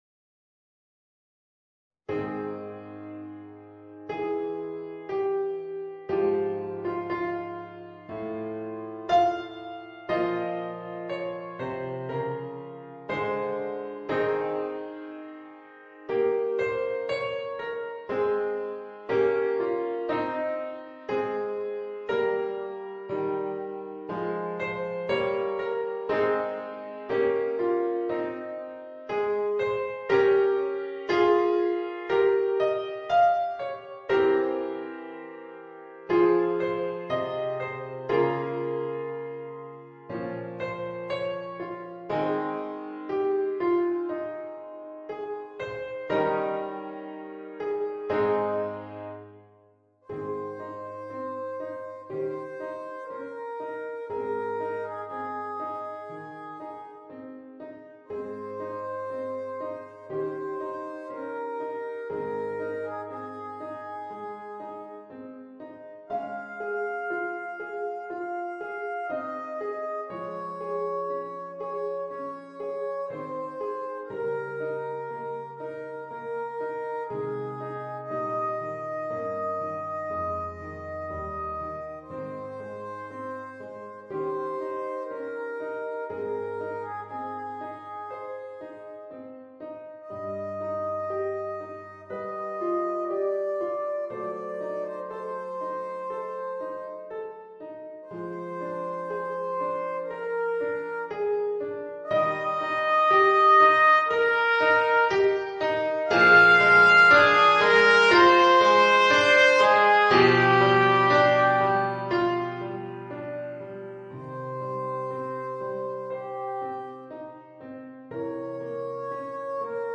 Trumpet and Piano Level